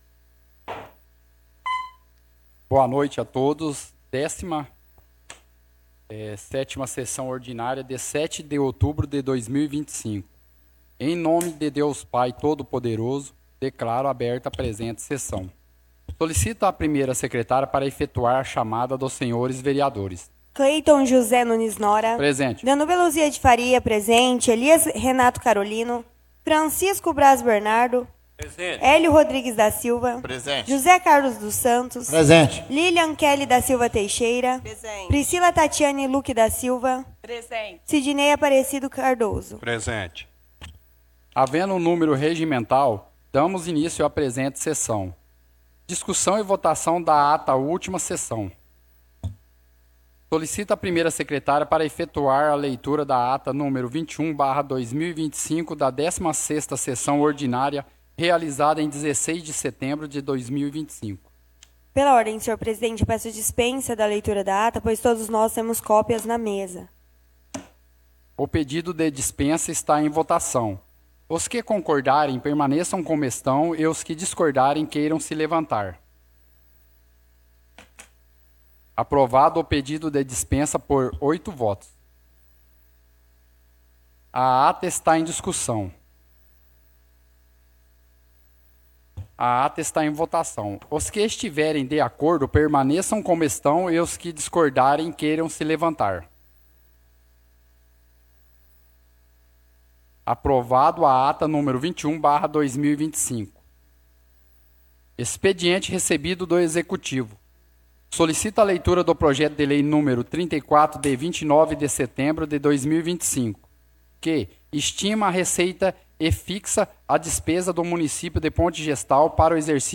Áudio da 17ª Sessão Ordinária – 07/10/2025